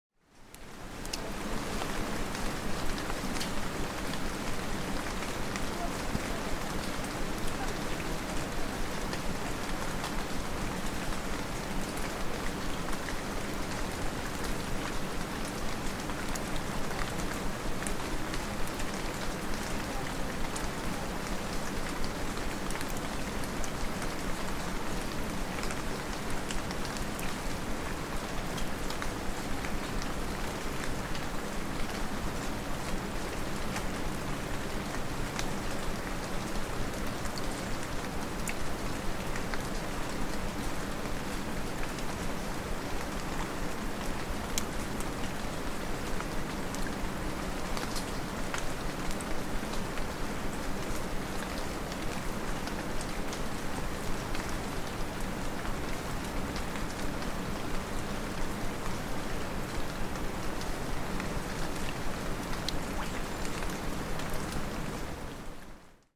This recording captures a moment I stoped on a walking along a "levada," a traditional water channel originally built to transport water from the north of the island.
It runs through a high-altitude area characterised by lush greenery and dense forest. The soundscape is dominated by the birds, wind, and the abundance of water—whether gently flowing through the channels or rushing in small streams. In this recording, alongside the sound of rain, you can hear the levada flowing to the right and a river farther below, creating a gentle, droning ambiance. Subtly in the background, the distant voices of other walkers.
UNESCO listing: Laurisilva of Madeira